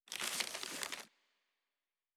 纸声.wav